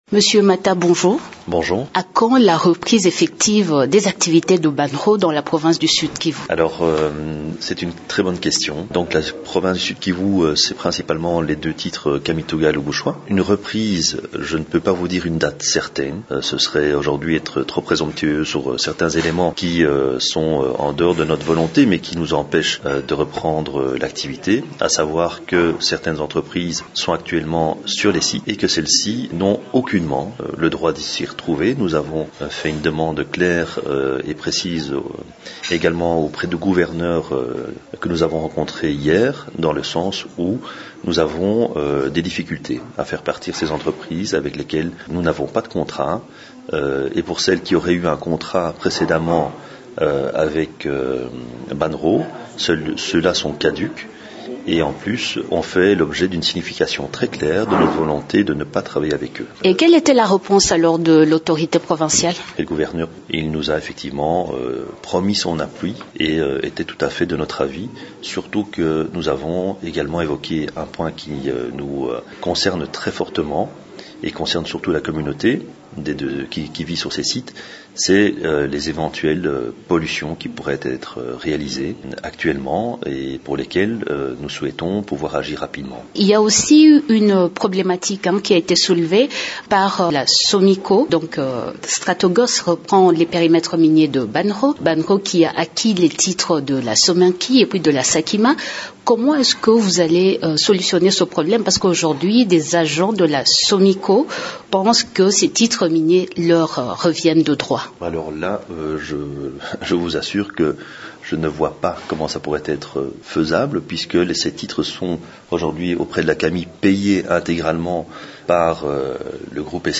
repond à ces questions au micro